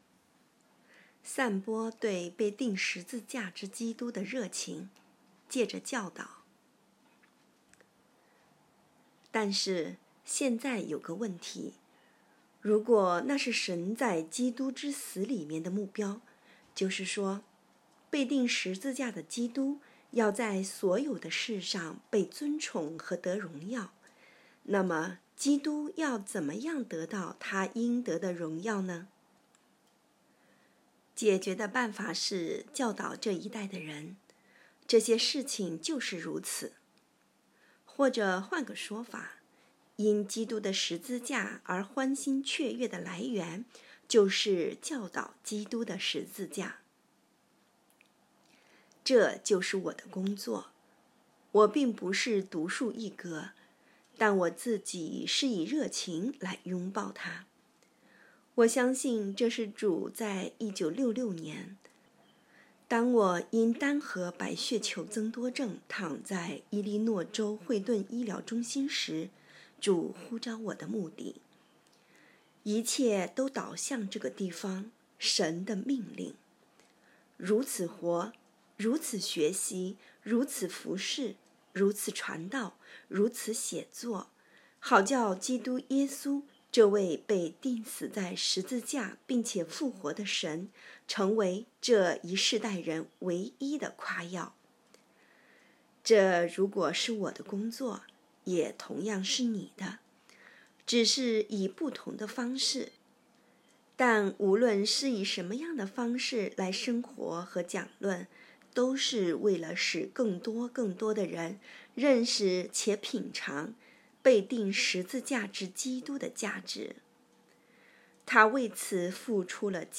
2023年10月5日 “伴你读书”，正在为您朗读：《活出热情》 音频 https